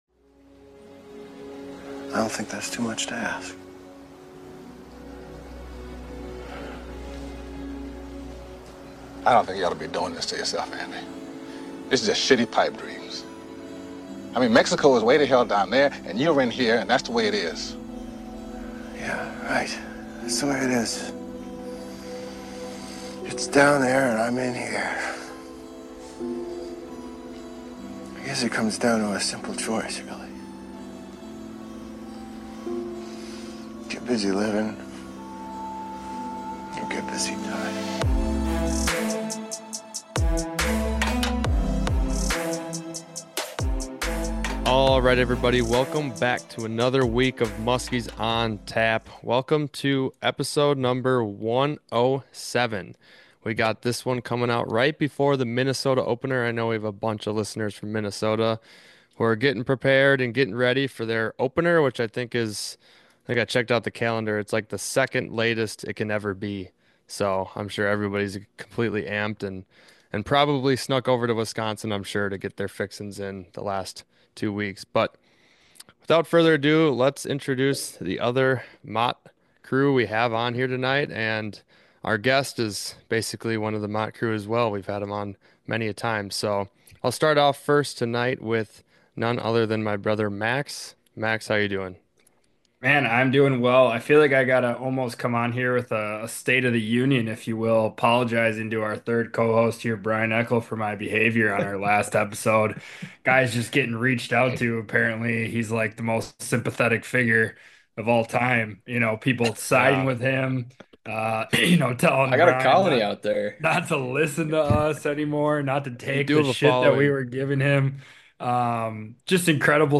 E101 - Live From Cave Run PMTT Preview – Muskies On Tap – Podcast